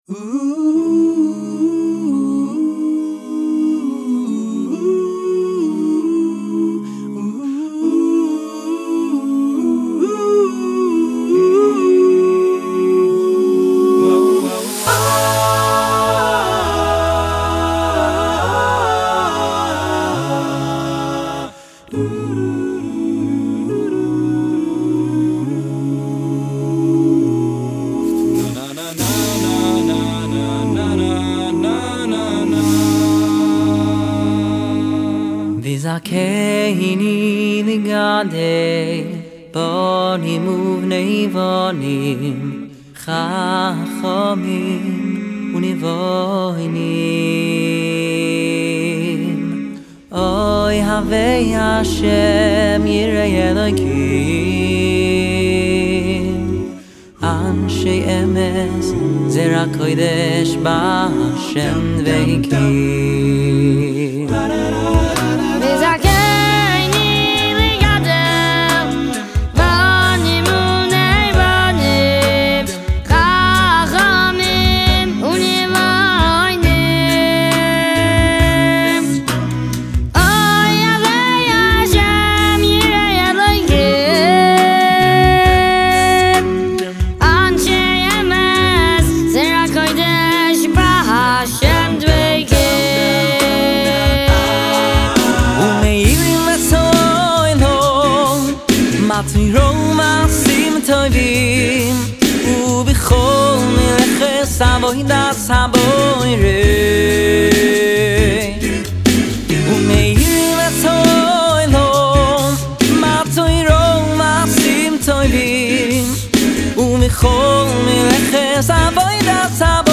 "וזכני לגדל בנים" ווקאלי- של מי הביצוע?